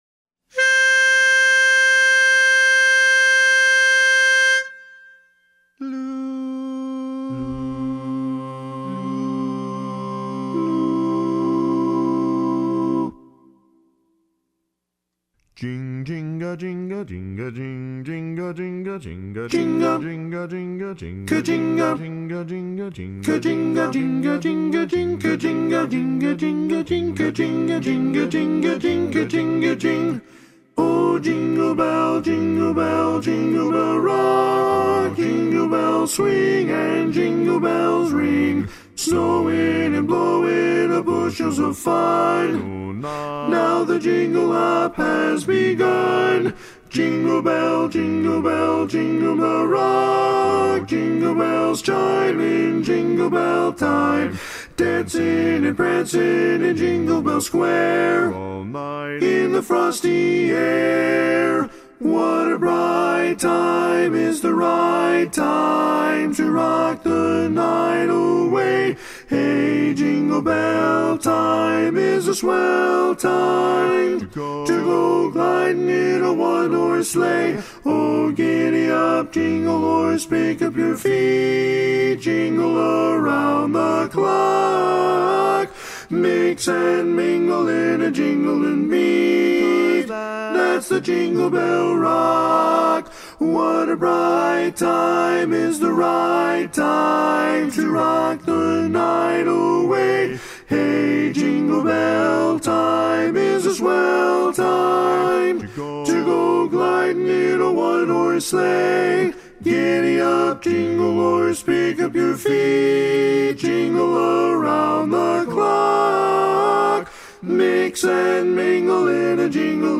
Christmas Songs
Up-tempo
Barbershop
Lead